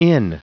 Prononciation du mot inn en anglais (fichier audio)
Prononciation du mot : inn